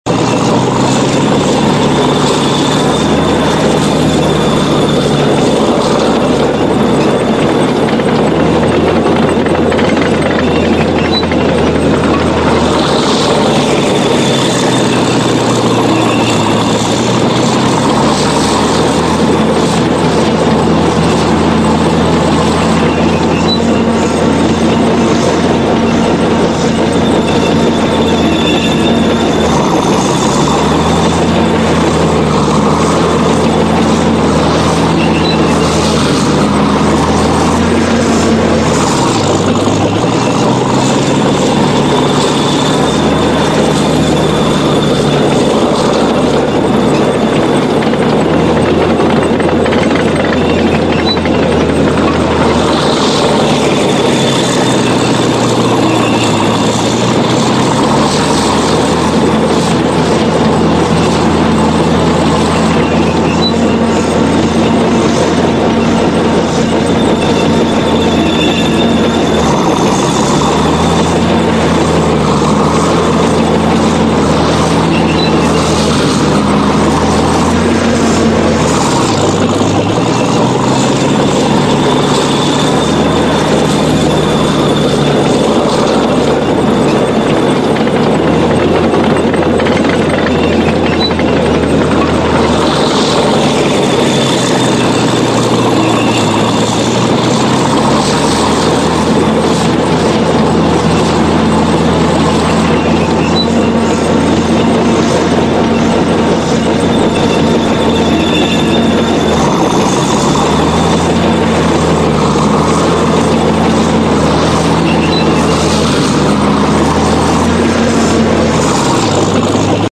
دانلود آهنگ تانک 1 از افکت صوتی حمل و نقل
جلوه های صوتی
دانلود صدای تانک 1 از ساعد نیوز با لینک مستقیم و کیفیت بالا